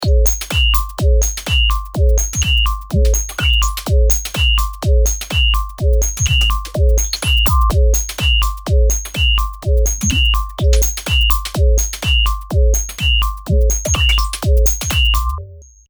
ブロック1はFilterのまま、ブロック2のエフェクトを“Shaper”変えてみましょう。エフェクトタイプは“Ring Mod”リングモジュレーターを選んでみました。
ドラムの音からこんなエフェクトが生み出されるとは！